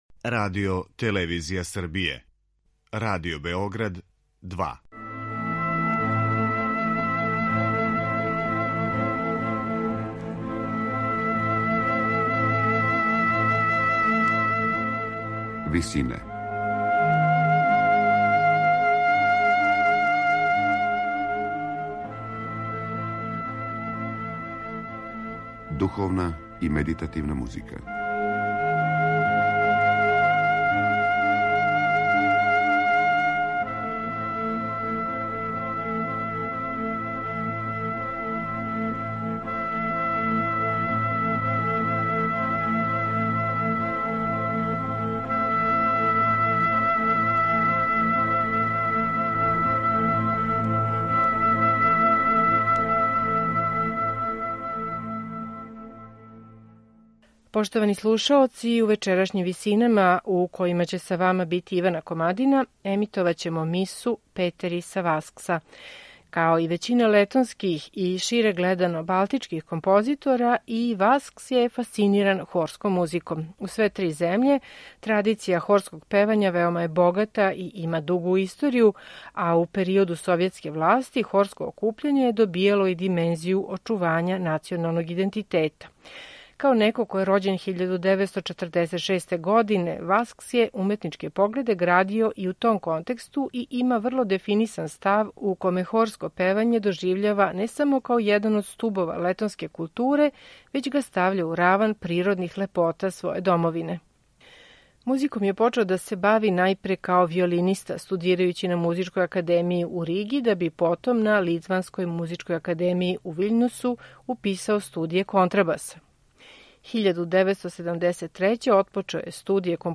а прерађеној 2005. за хор и гудачки оркестар.